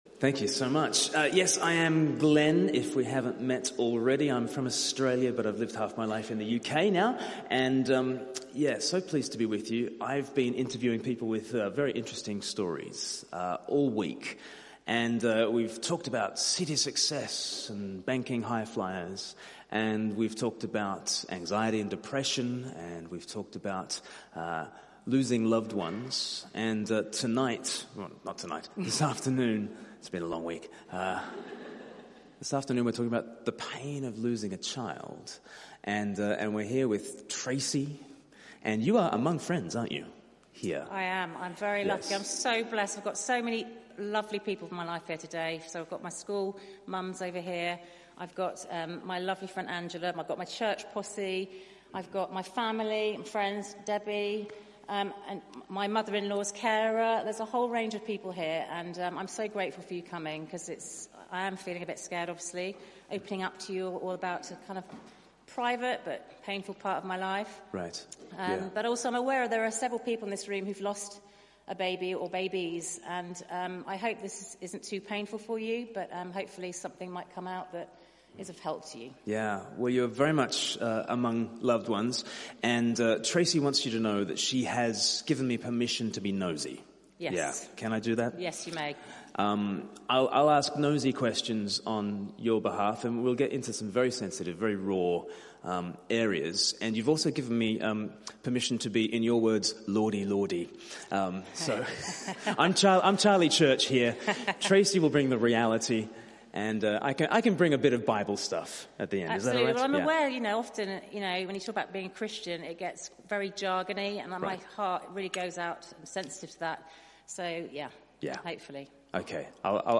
The Pain of Losing a Child Interview Talk Search the media library There are recordings here going back several years.